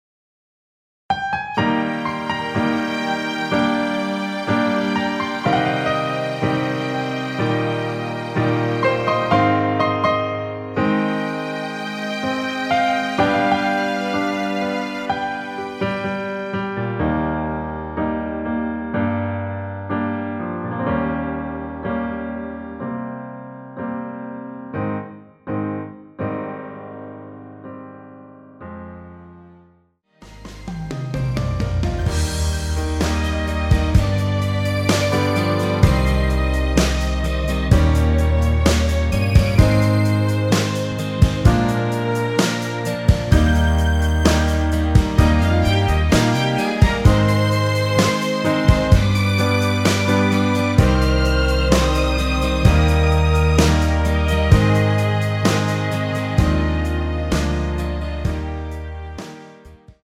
원키에서(-2)내린 MR입니다.
Eb
앞부분30초, 뒷부분30초씩 편집해서 올려 드리고 있습니다.
중간에 음이 끈어지고 다시 나오는 이유는